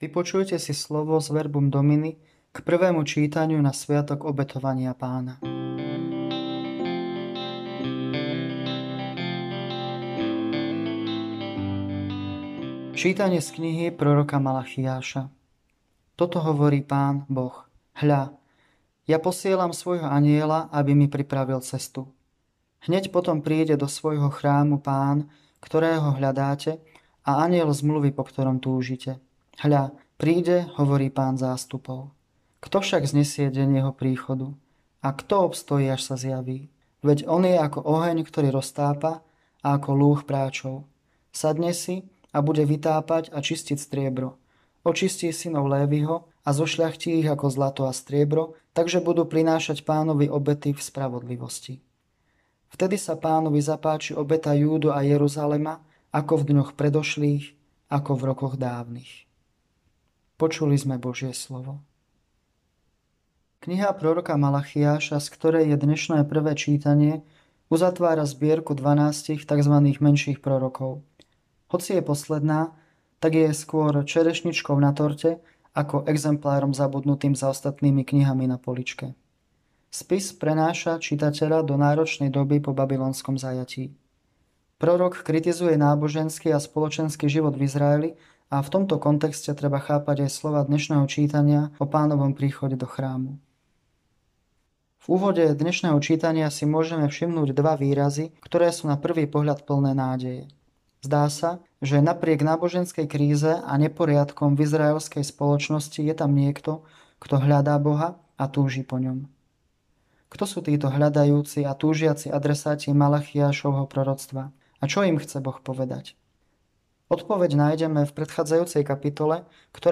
Audionahrávka zamyslenia…
Hudba: Vykupiteľky – Moje srdce